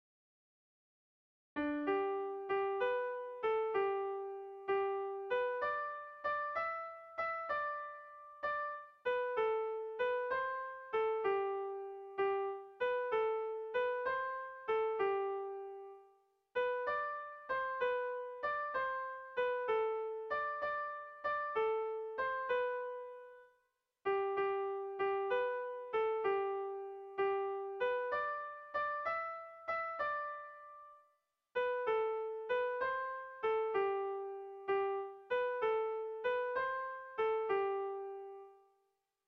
Kontakizunezkoa
Hamarreko txikia (hg) / Bost puntuko txikia (ip)
ABDAB